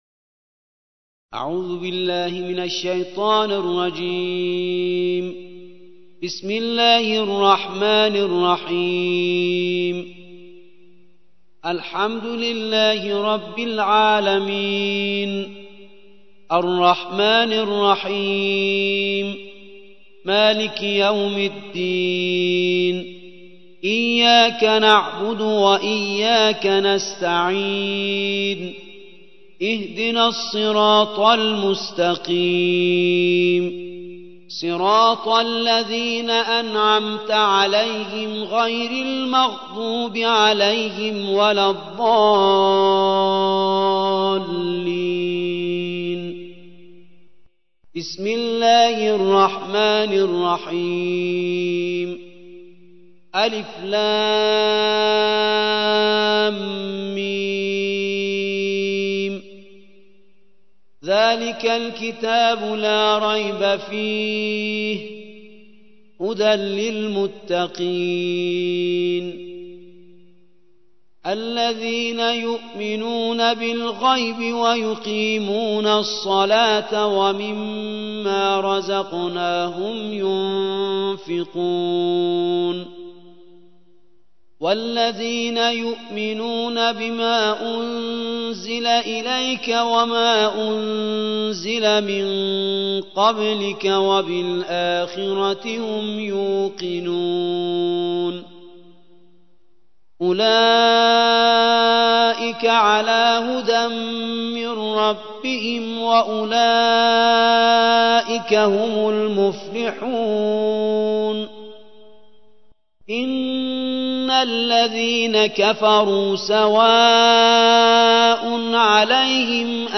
الجزء الأول / القارئ